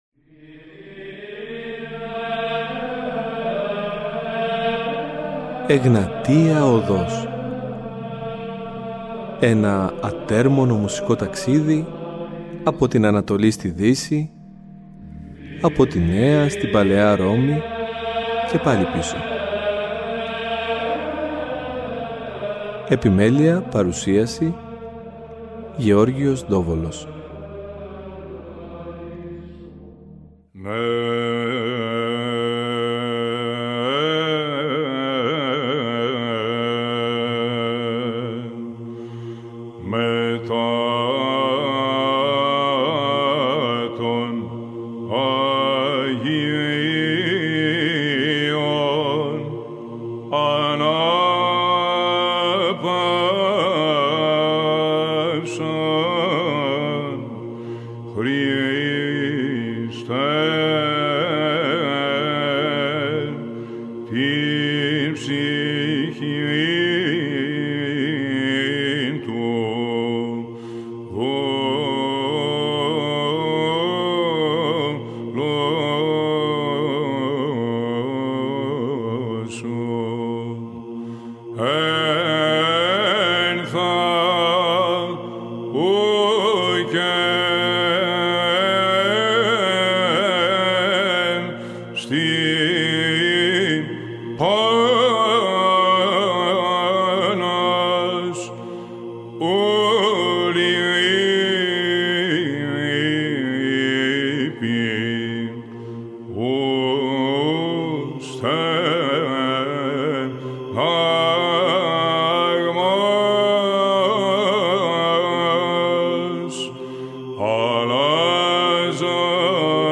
Στις δύο εκπομπές ακούγονται ξεχωριστές ερμηνείες από βυζαντινούς χορούς αλλά και παραδοσιακούς ψάλτες που θα αποδώσουν τον Άμμωμο, τα Ευλογητάρια, τους νεκρώσιμους Μακαρισμούς, τα Ιδιόμελα, αλλά και άγνωστα μαθήματα που έχουν κατά καιρούς συνθέσει καταξιωμένοι βυζαντινοί και μεταβυζαντινοί μελοποιοί.